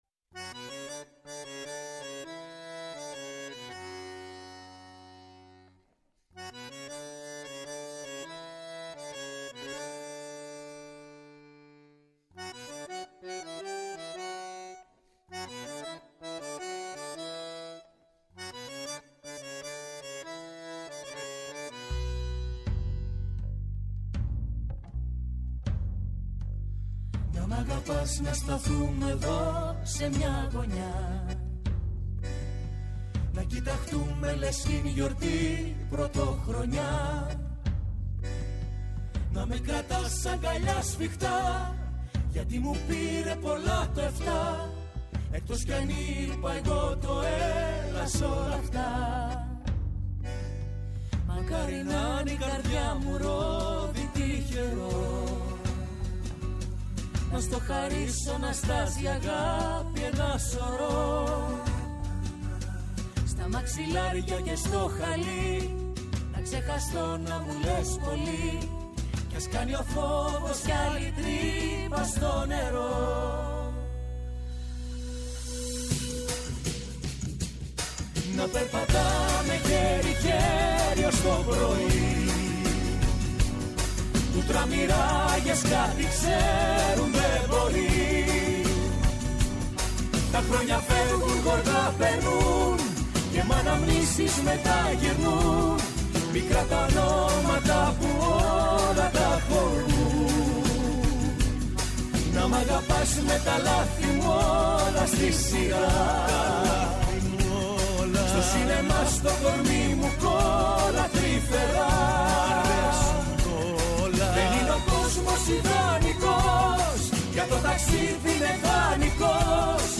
Καλεσμένος στο στούντιο ο ηθοποιός Ζερόμ Καλούτα.